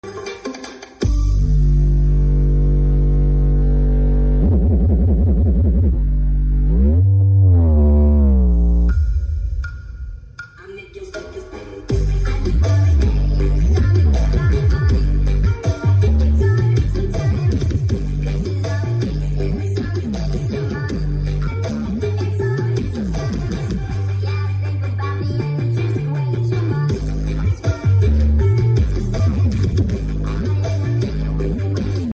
Cek sound ngamen 16 Sub sound effects free download